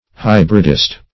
Hybridist \Hy"brid*ist\, n. One who hybridizes.